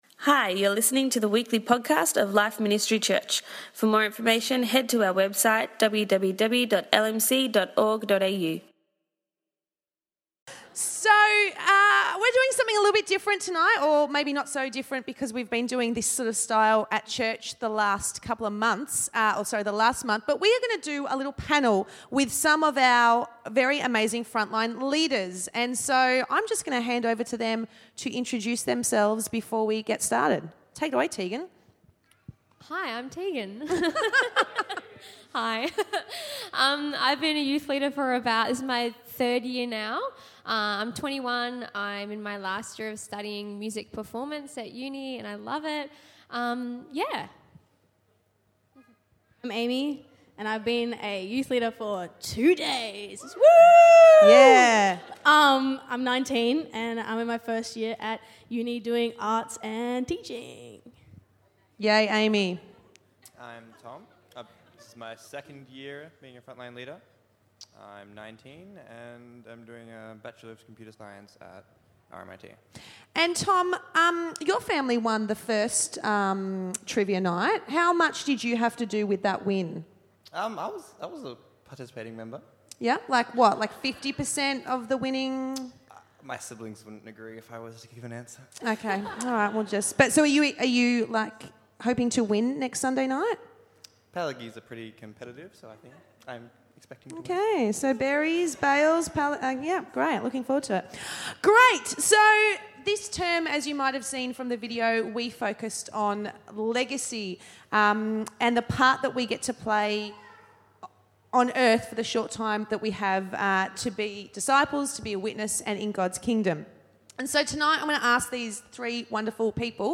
Legacy Panel
Tonight we heard from 3 of our amazing Frontline Youth Leaders discussing and reflecting on the youth's term 1 topic 'Legacy'.